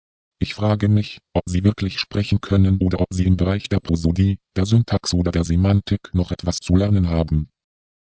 Bsp12 mit kompletter Intonation).